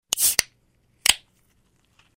SFX – POP CAN OPEN
SFX-POP-CAN-OPEN.mp3